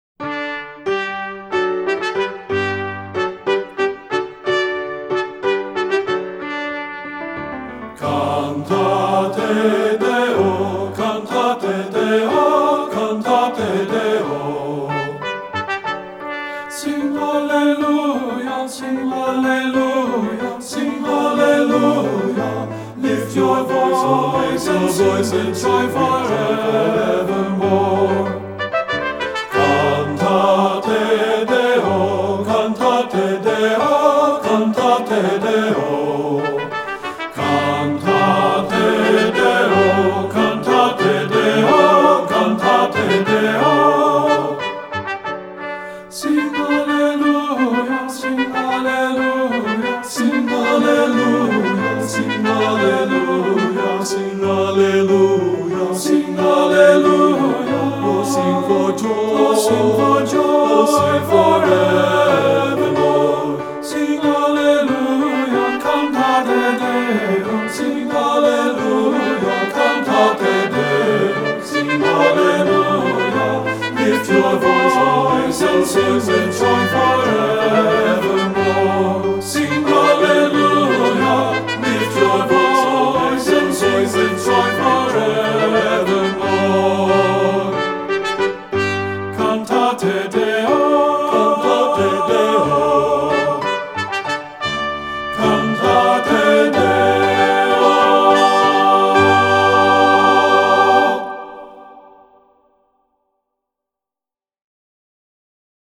Voicing: TB and Piano